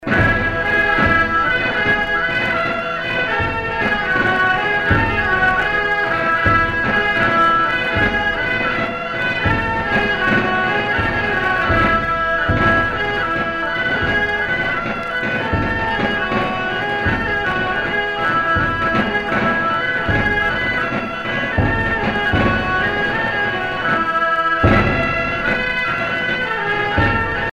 gestuel : à marcher
Pièce musicale éditée